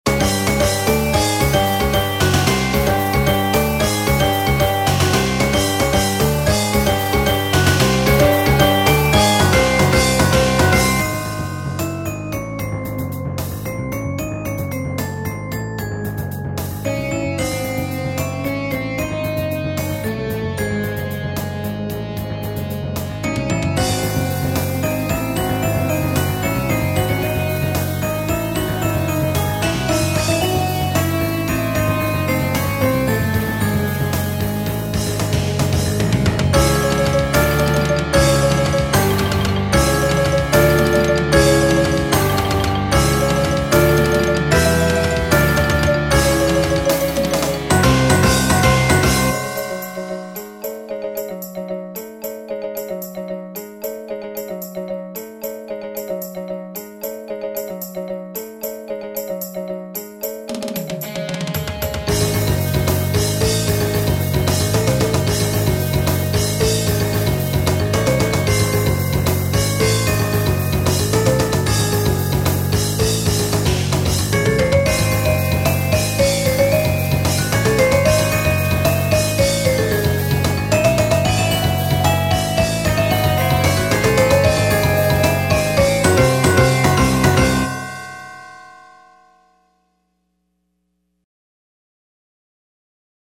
Medium Percussion Ensemble